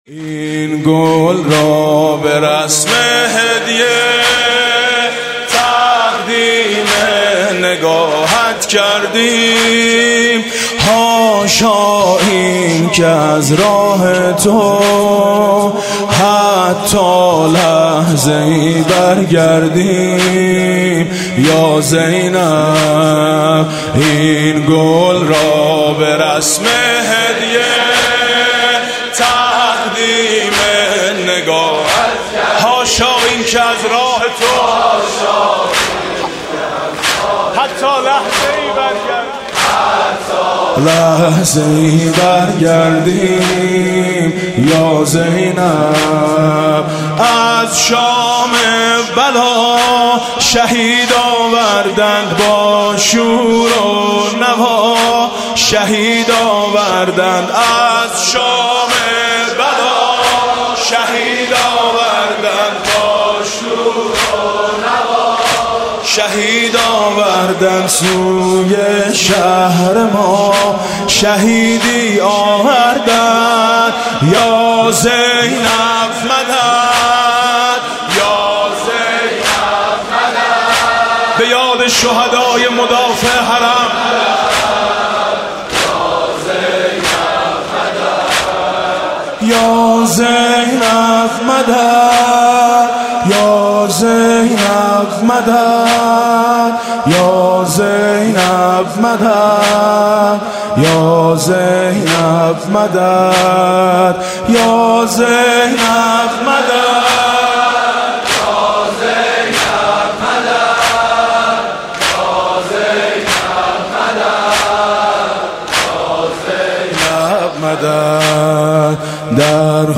مداحی صوتی